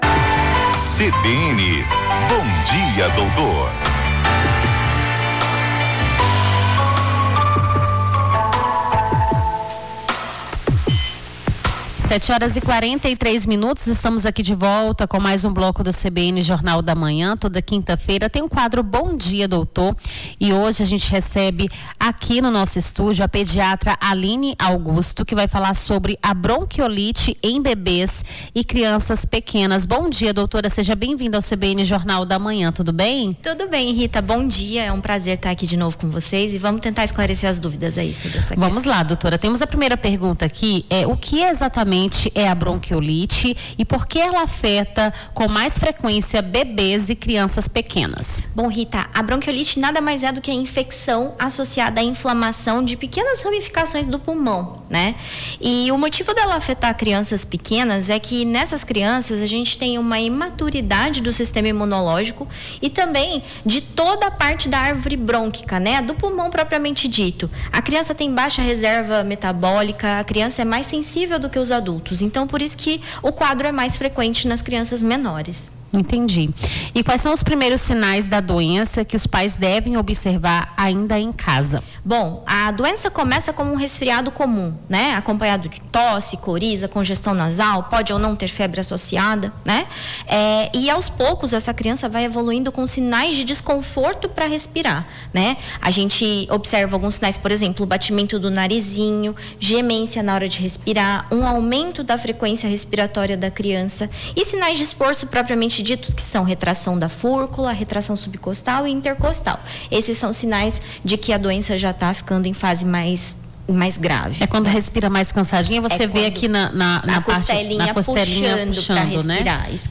a pediatra